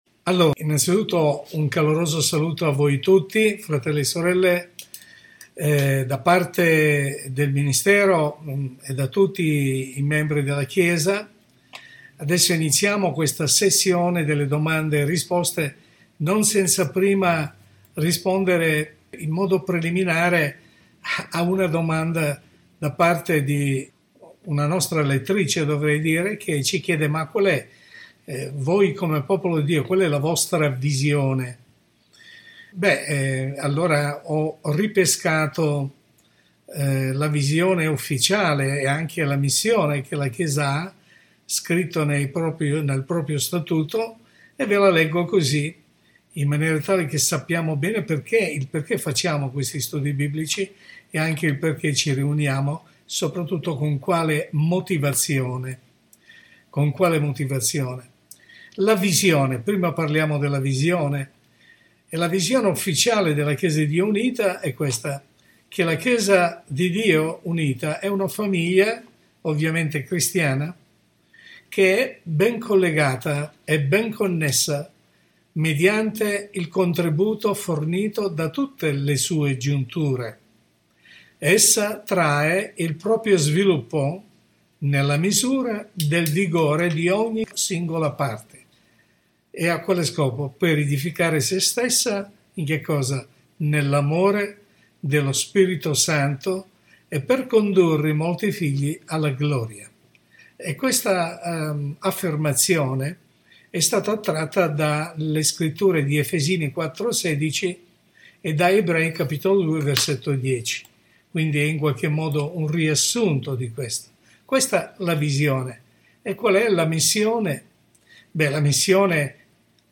Studio Biblico